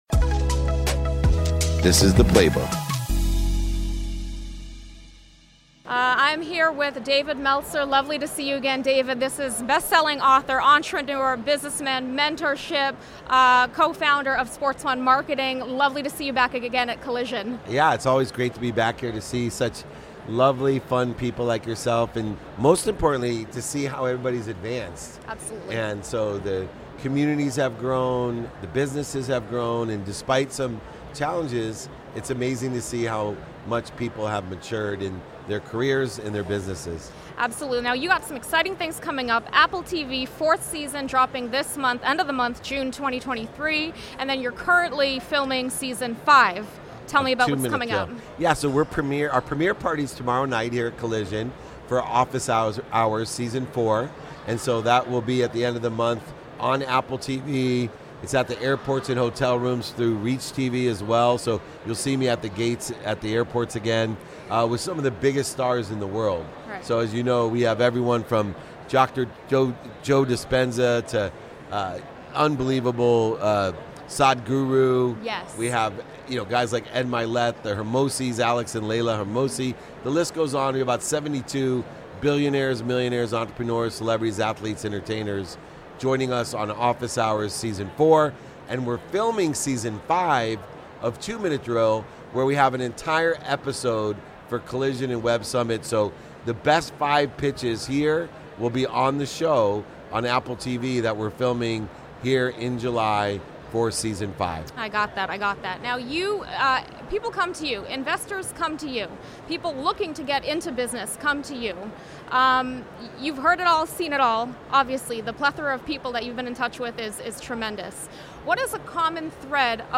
This episode is a conversation